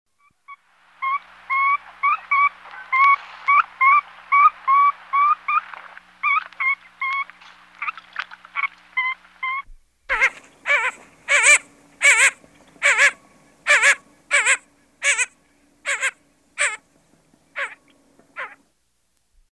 Blue-Winged Teal
Males a whistle-sounding peep or bleat; females have a loud, low quack.
blue-winged-teal.mp3